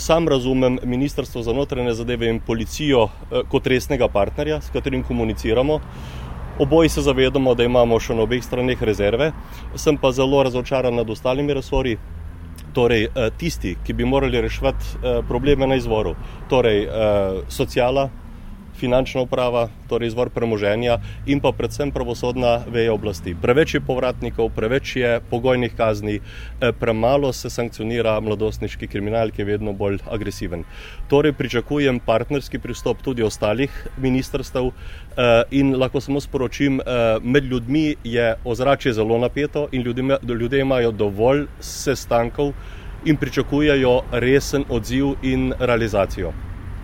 Župan Šentjerneja Jože Simončič je razočaran nad delom nekaterih ministrstev